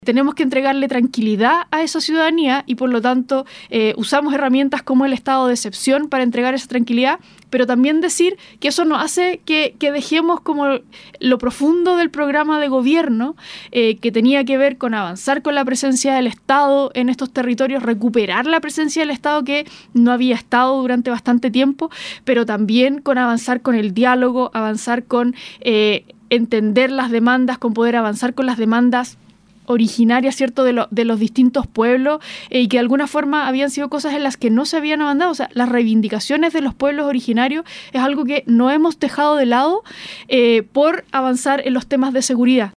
En conversación con Nuestra Pauta, la autoridad política recalcó que a nivel regional «ha sido un año complejo» por distintas razones, pero que «me enorgullece el trabajo que hemos hecho, porque siento que hemos avanzado en las cosas que a la gente le preocupan realmente».